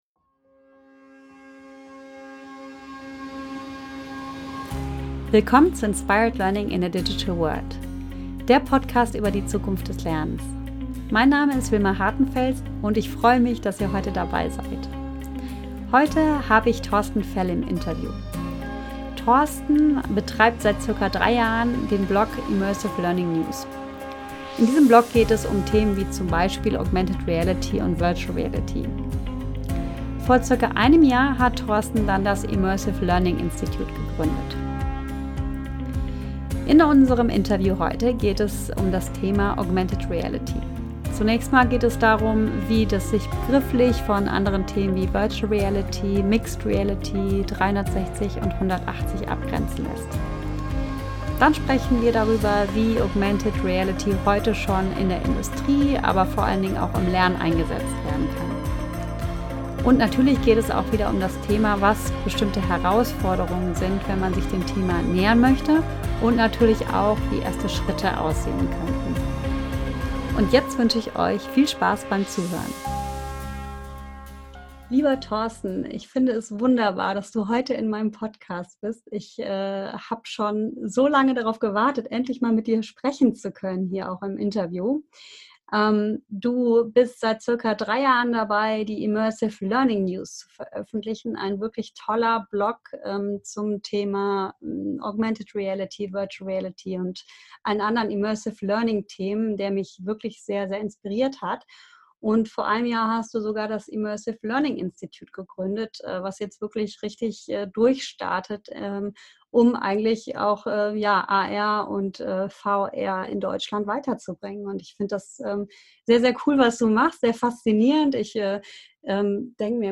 Heute interviewe ich